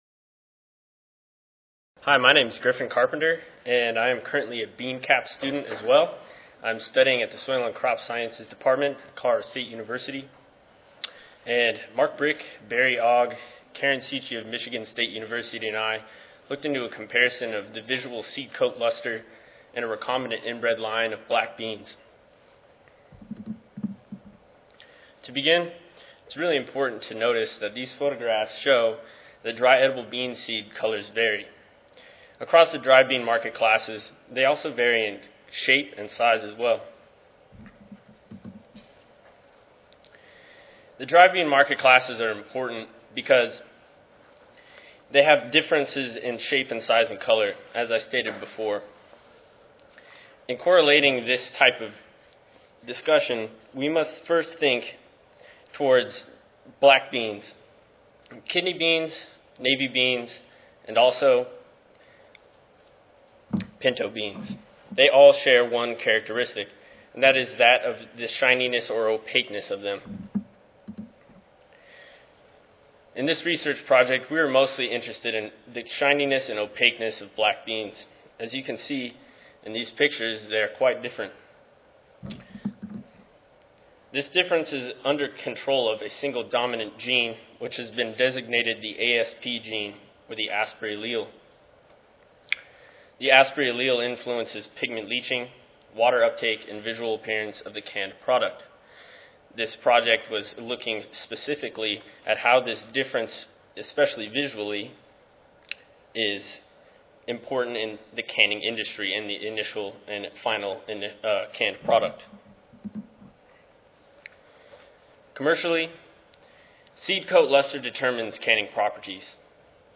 USDA-ARS Recorded Presentation Audio File